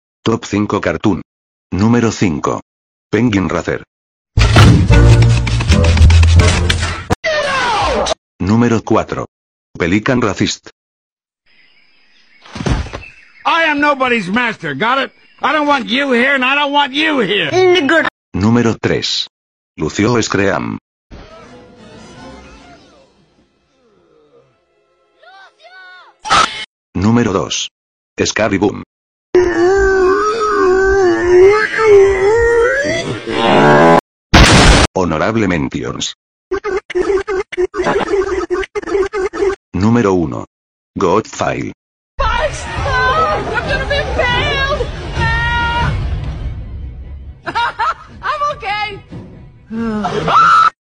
Top 5 Cartoon Sound Effects Free Download